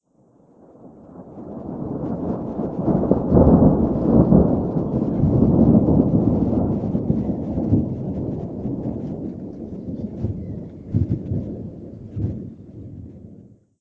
A320-family/Sounds/Effects/thunder1.wav at 9163fd3855ce6f6e3adec7812f0f00ee3bc5b08d
thunder1.wav